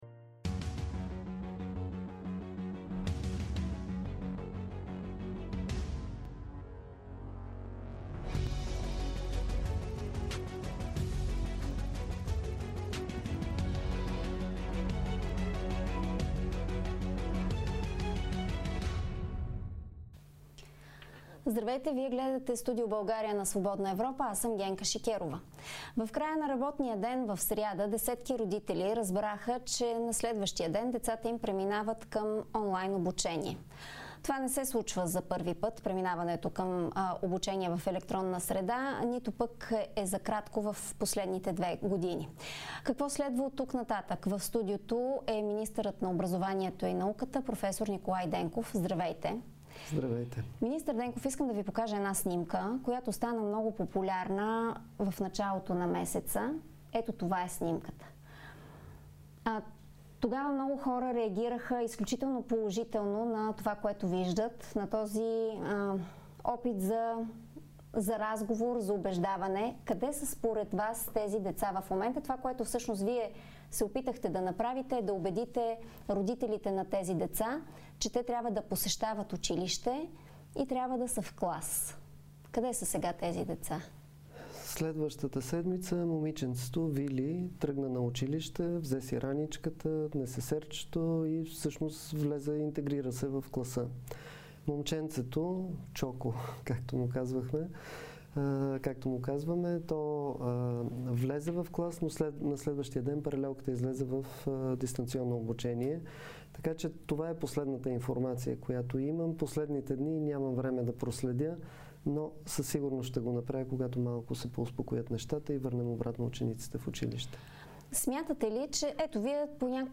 Кога учениците ще се върнат в училищата? На този и други въпроси отговаря министърът на образованието Николай Денков в Студио България на Свободна Европа.